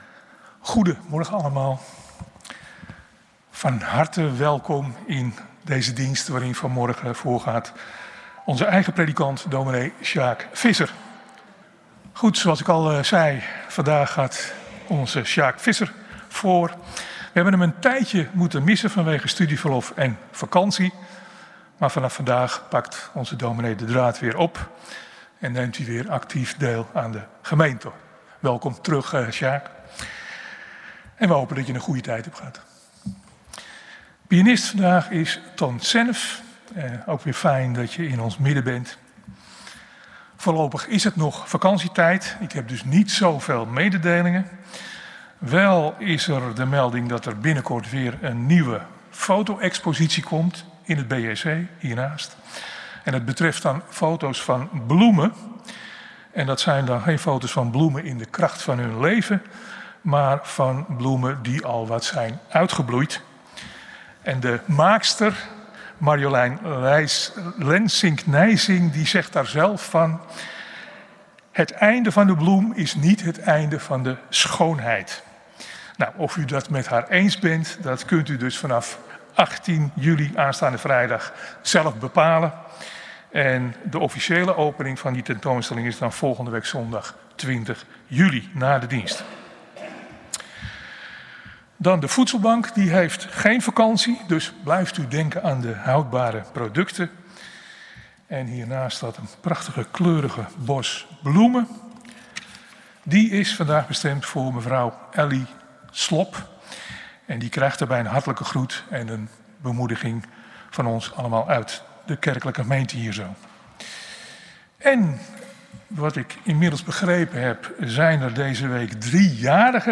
Kerkdiensten
Kerkdienst geluidsopname